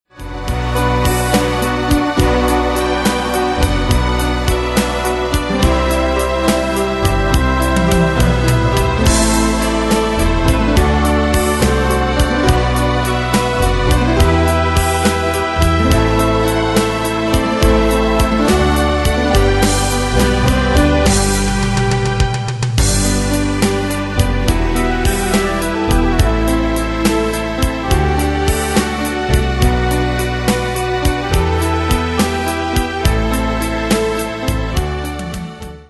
Style: PopFranco Année/Year: 1971 Tempo: 70 Durée/Time: 2.30
Danse/Dance: Ballade Cat Id.
Pro Backing Tracks